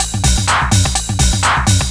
DS 126-BPM A02.wav